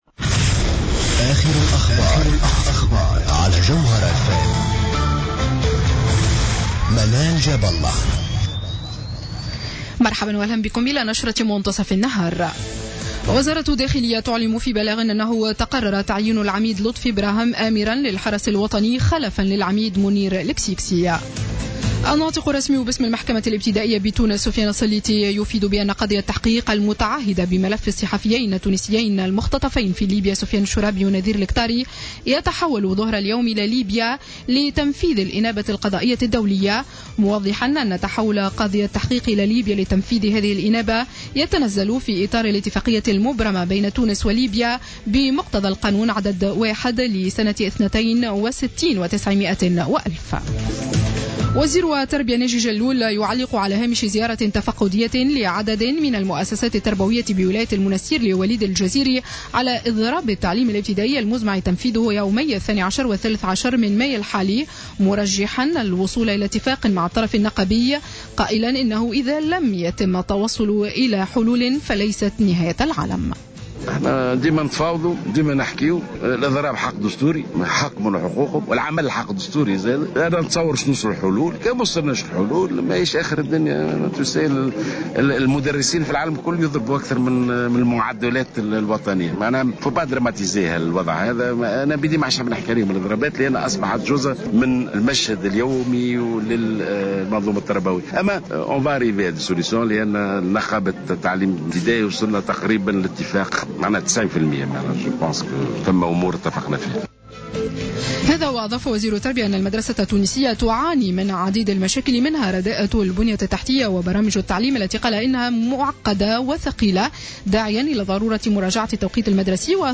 نشرة أخبار منتصف النهار ليوم السبت 02 ماي 2015